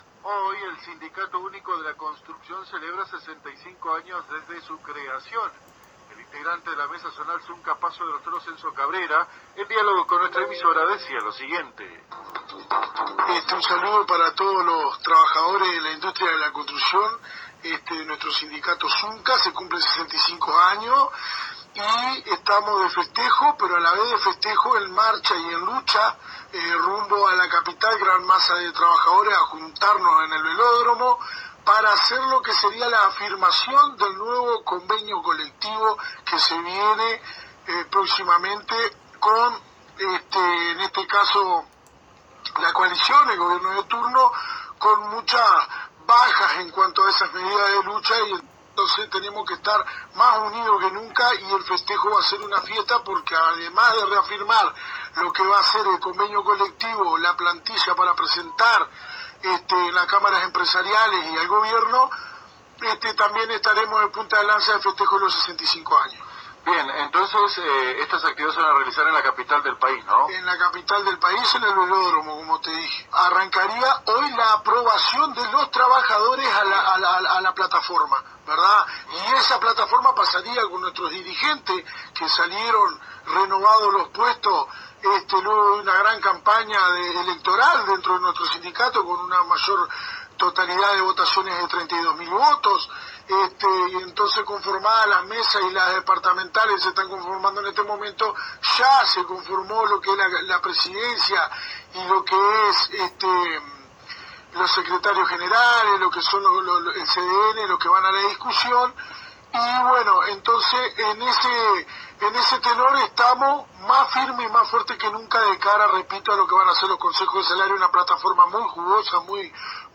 Fuente: AM 1110 Radio Paso de los Toros